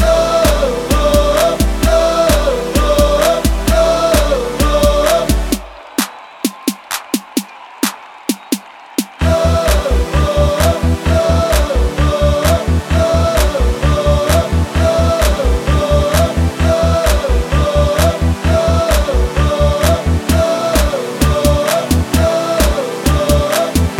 no Backing Vocals with whoas R'n'B / Hip Hop 4:29 Buy £1.50